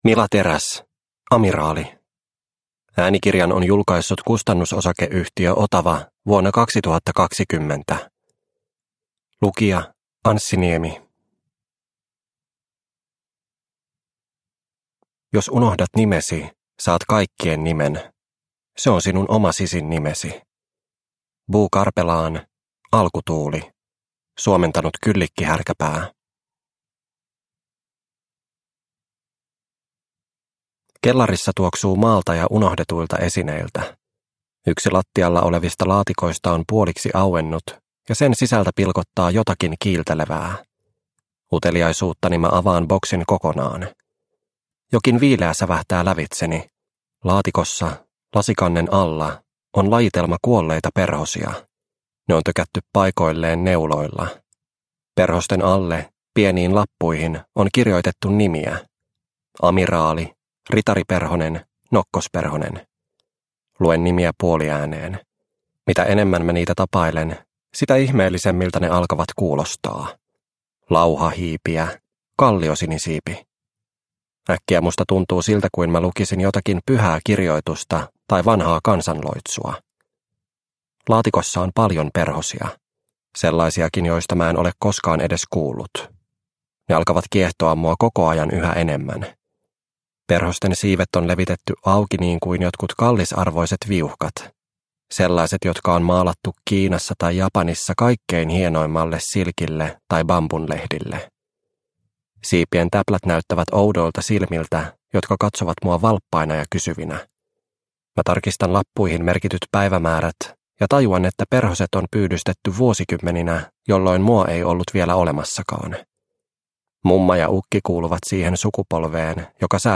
Amiraali – Ljudbok – Laddas ner